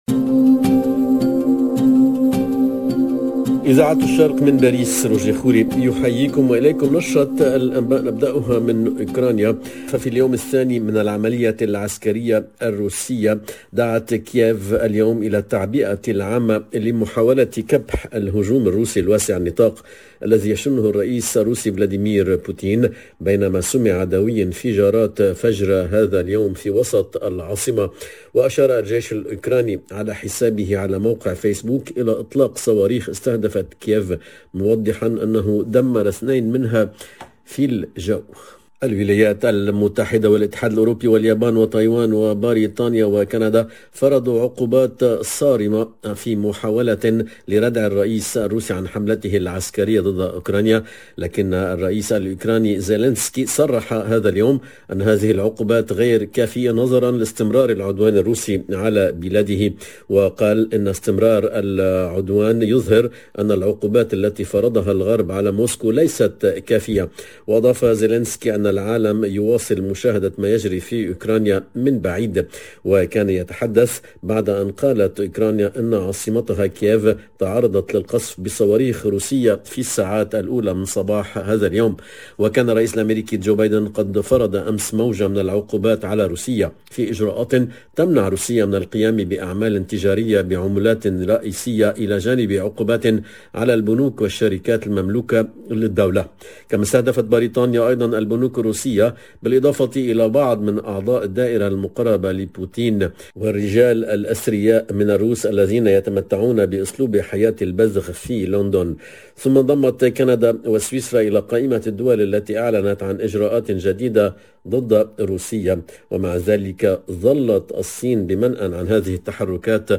LE JOURNAL DE MIDI 30 EN LANGUE ARABE DU 25/02/22